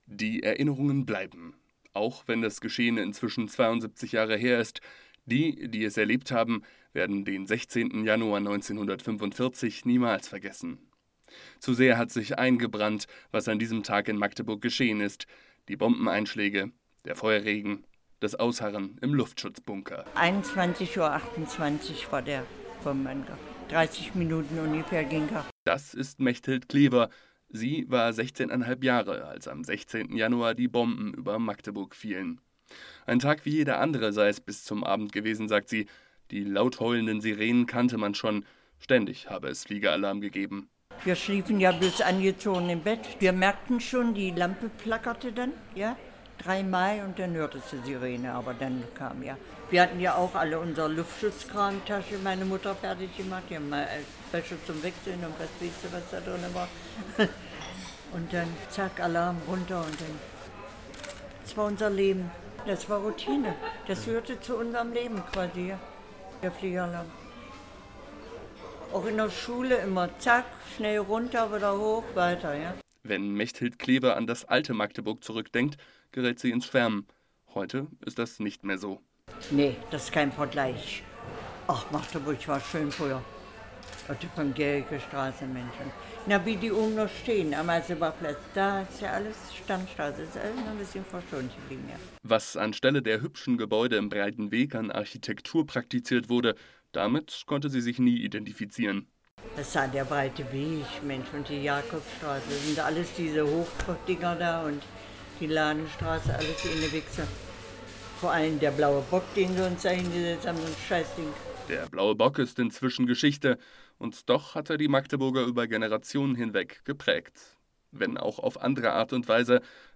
Beitrag
h2radio hat sich anlässlich der Live-Sendung vom 16. Januar 2017 näher mit der Bombardierung Magdeburgs vor 72 Jahren beschäftigt. Wir lassen eine Zeitzeugin zu Wort kommen und waren im Magdeburger Stadtarchiv, wo die Geschehnisse des 16. Januar 1945 wissenschaftlich aufbereitet wurden.